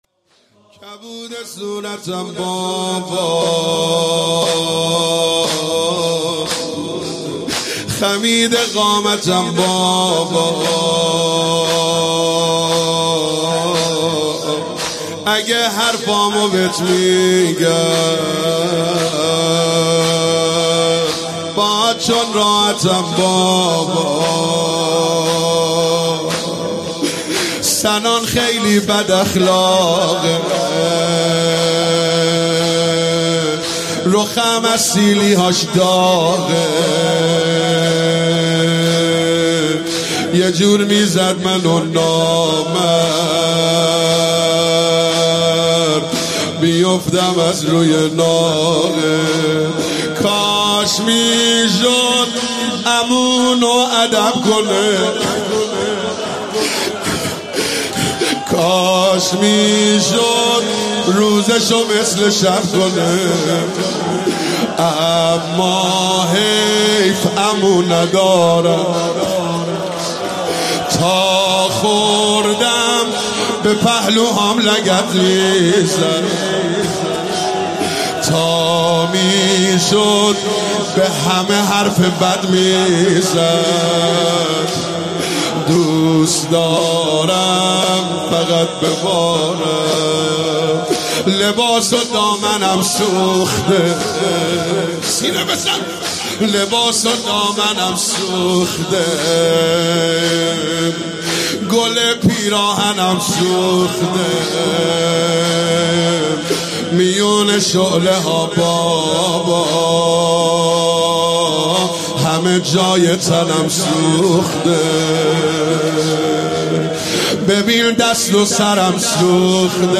شب سوم محرم97 هیئت یا فاطمه الزهرا (س) بابل